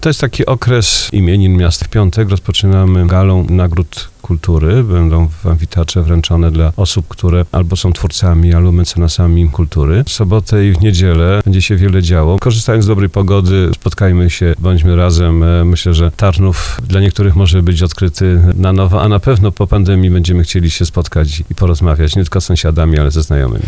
Jak podkreślił na antenie Radia RDN Małopolska prezydent Tarnowa, Roman Ciepiela, program Dni Tarnowa jest bardzo bogaty, a po okresie pandemii będzie to dla mieszkańców miasta znakomita okazja, aby spotkać się i być może, odkryć Tarnów na nowo.